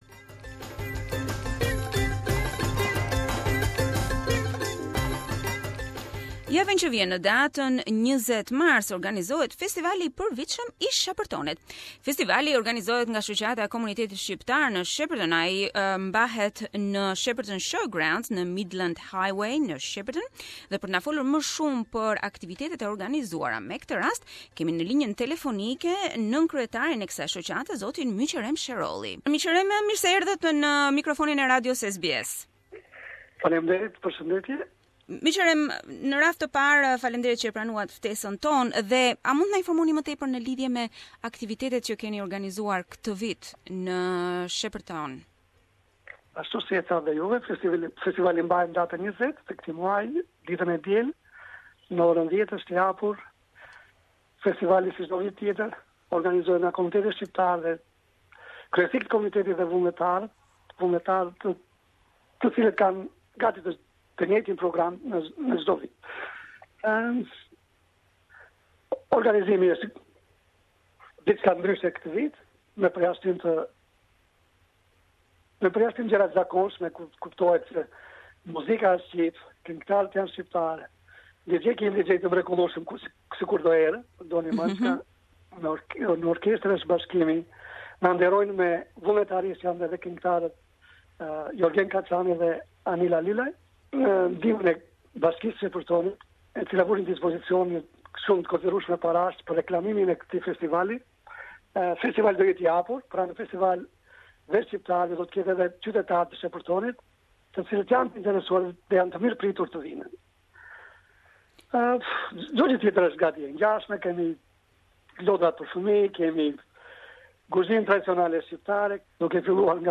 Interview with representatives of the Albanian Community in Shepparton